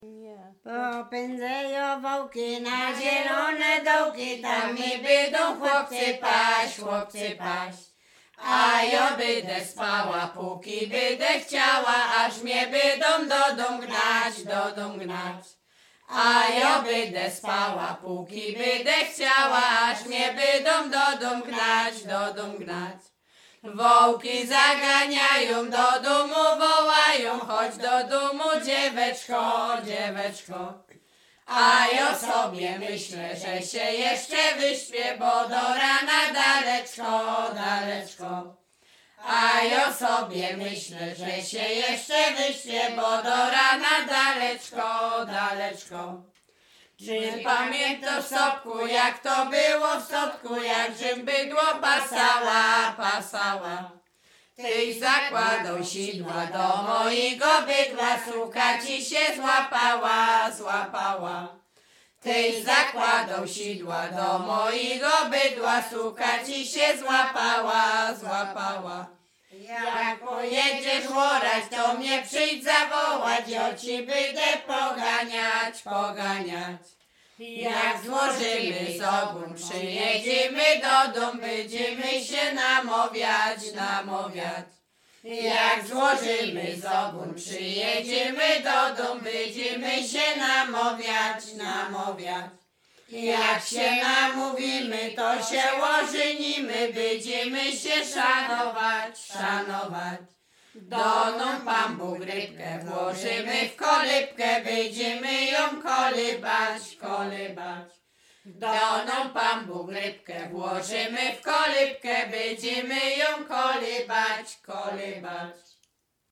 Śpiewaczki z Mroczek Małych
województwo łodzkie, powiat sieradzki, gmina Błaszki, wieś Mroczki Małe
miłosne liryczne